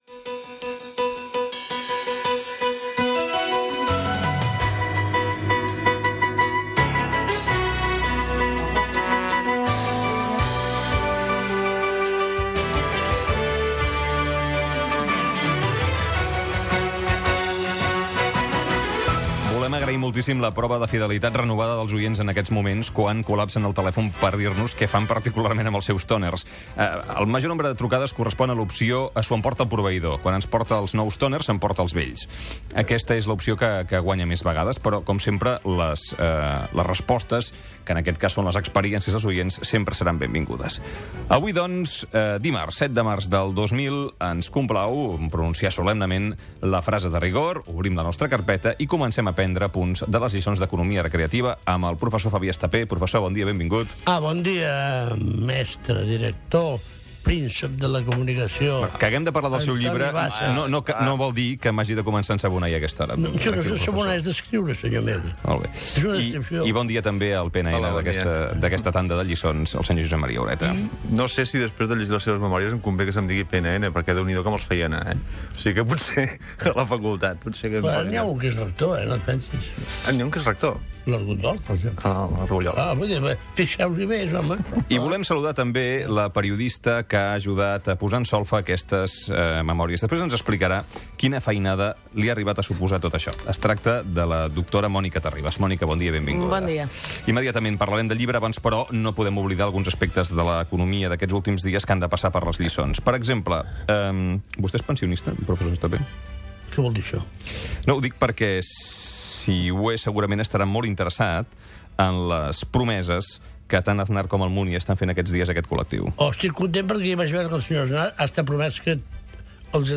Indicatiu de l'emissora.
Info-entreteniment